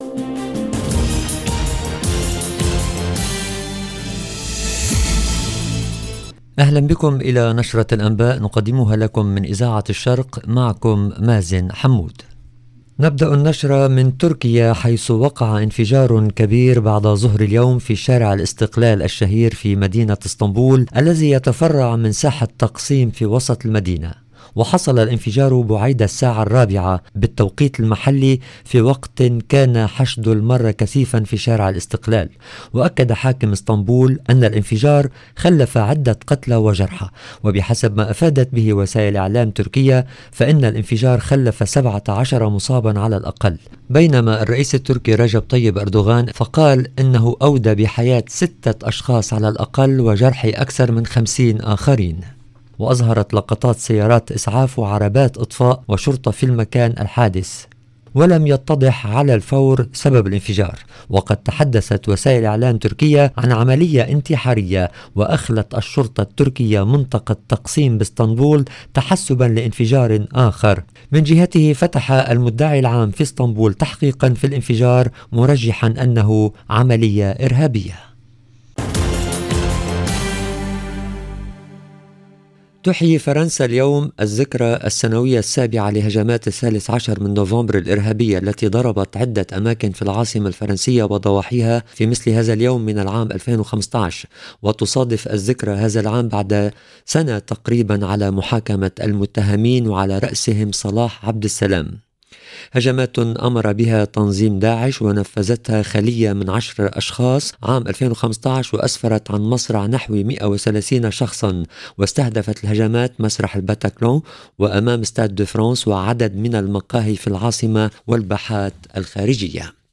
EDITION DU JOURNAL DU SOIR EN LANGUE ARABE DU 13/11/2022